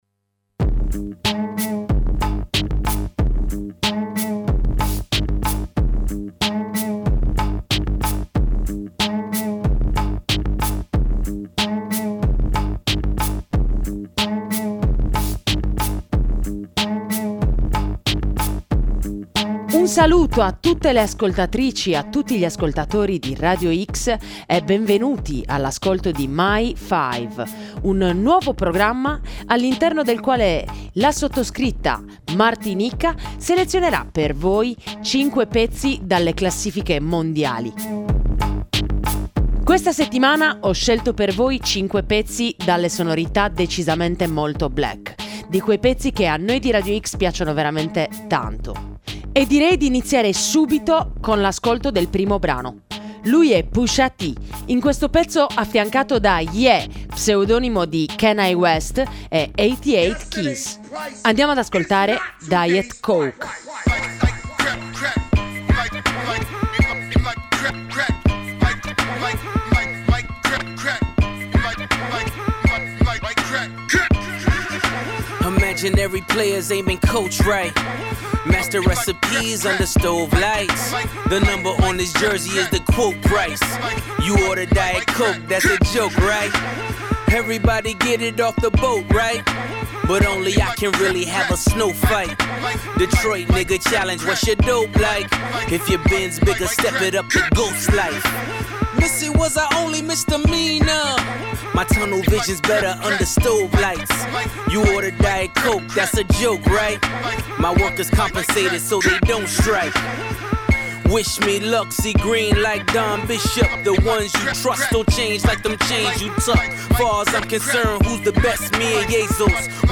hip hop / rap / trap